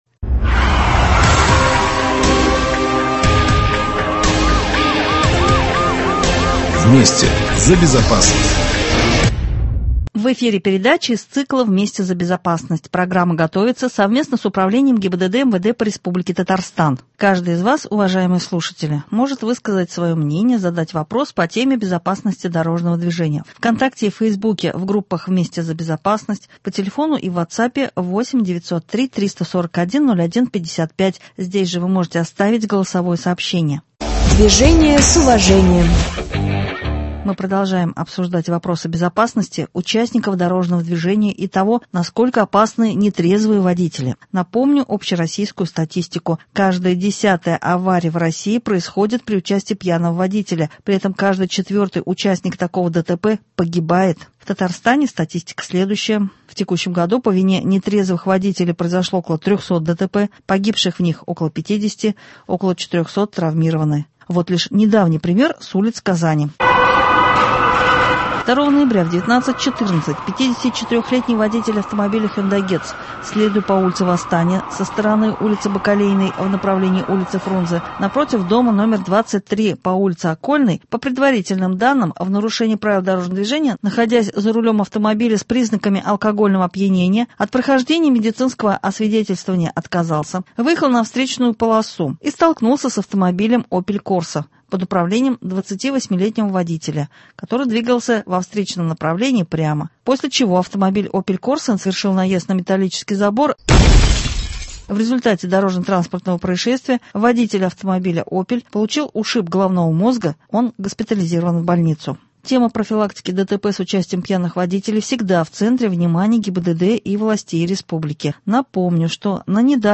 Сегодня участники передачи — сотрудники ГИБДД, медик и сами водители.